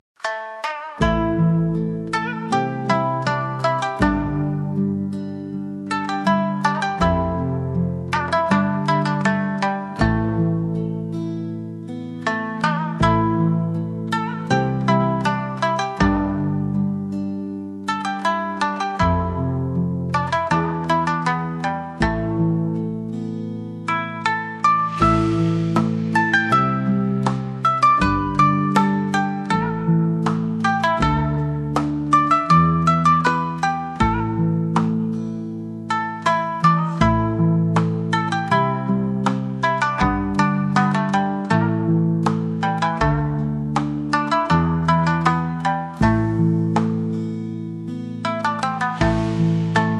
wyjątkowa kolekcja instrumentalnych utworów relaksacyjnych